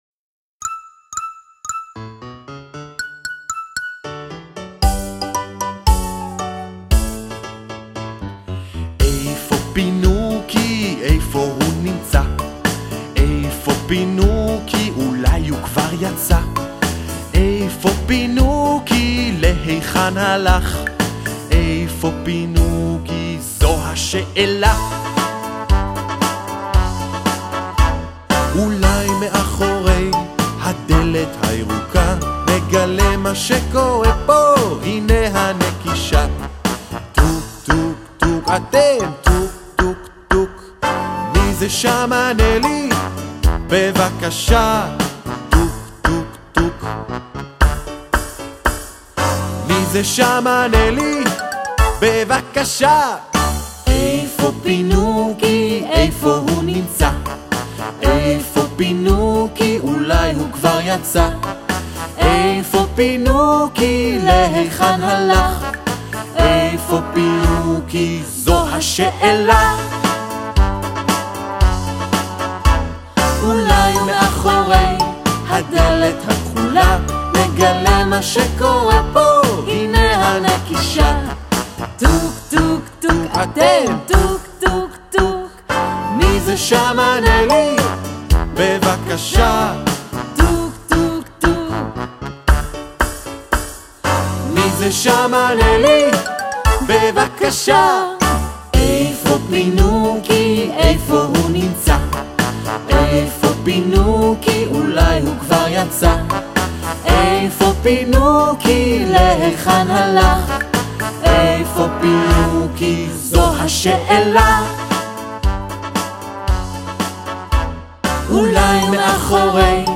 מבחר שירים מתוך ההצגה :